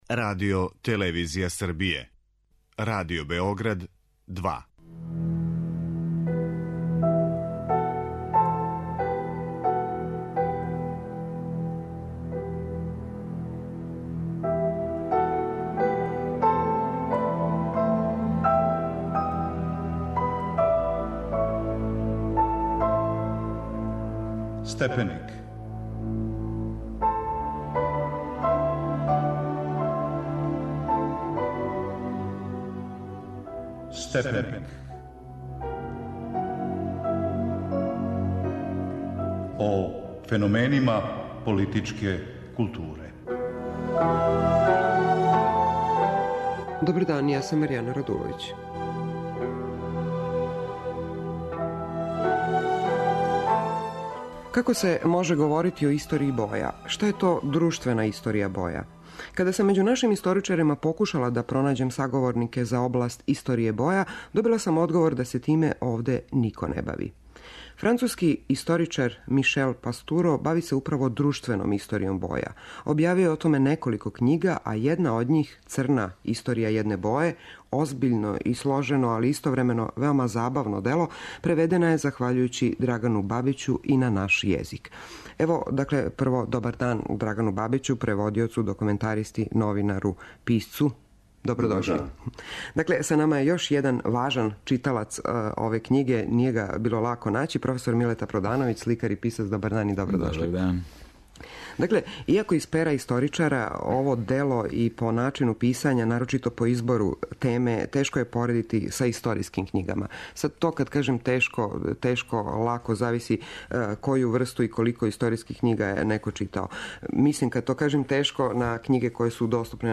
разговора